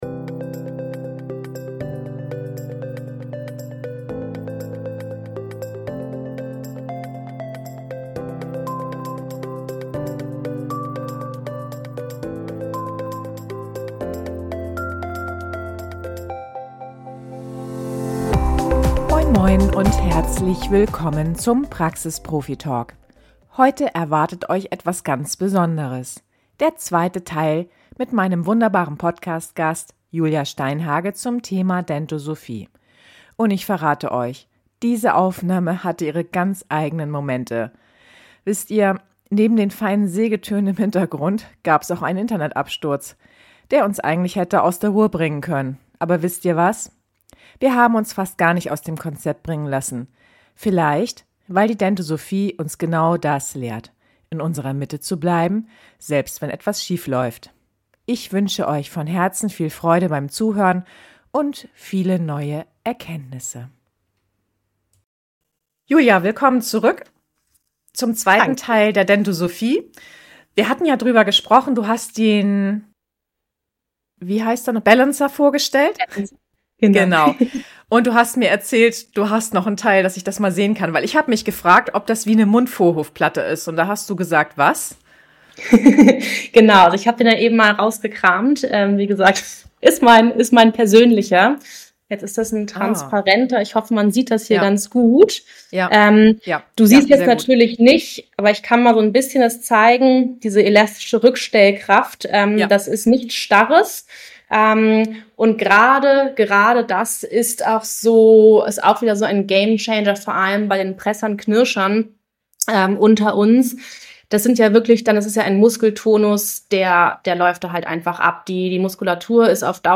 Und ich verrate euch: Diese Aufnahme hatte ihre ganz eigenen Momente. (leicht schmunzelnd) Wisst ihr, neben den feinen Sägetönen im Hintergrund gab's auch einen Internet-Absturz, der uns eigentlich hätte aus der Ruhe bringen können.